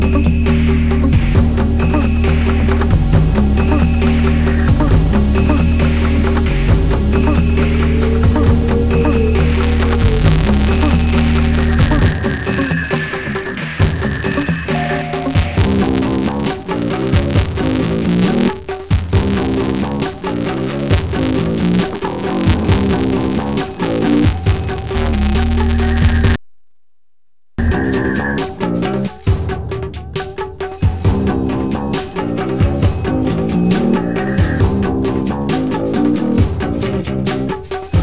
.  The static
> begins at 10 seconds into the clip.  At 26 seconds, I pause and unpause mpd,
> which resolves the problem for a few minutes.
opensolaris-audiohd-static.ogg